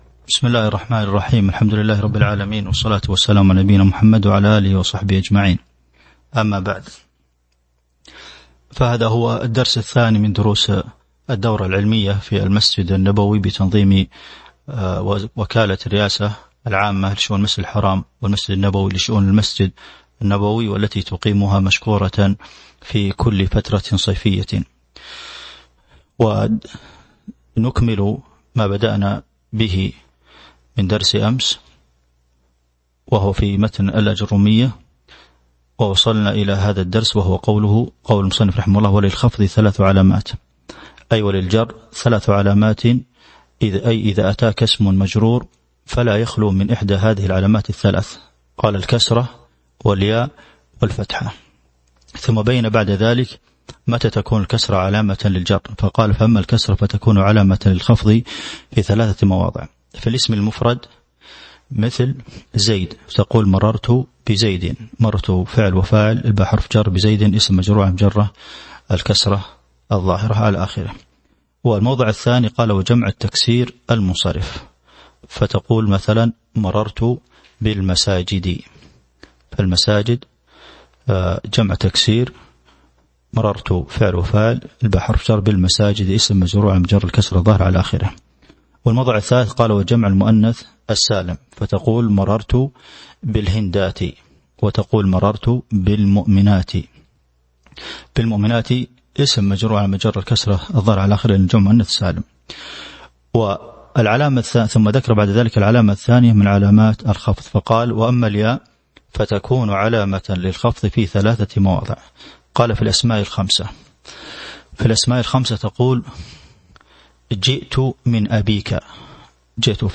تاريخ النشر ٤ ذو القعدة ١٤٤٢ هـ المكان: المسجد النبوي الشيخ: فضيلة الشيخ د. عبدالمحسن بن محمد القاسم فضيلة الشيخ د. عبدالمحسن بن محمد القاسم قوله: وللخفض ثلاث علامات (02)